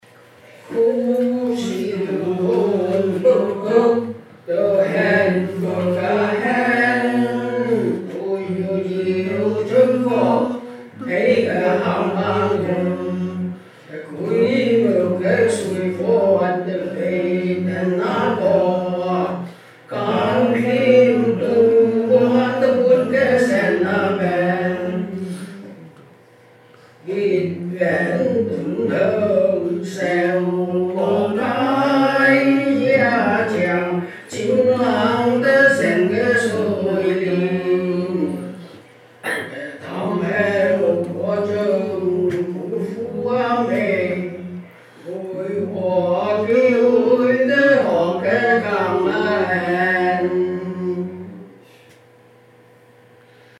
Each week, members of the choral study group assemble to learn a thematically related archival piece of vocal music by ear.
Week 2 Rehearsal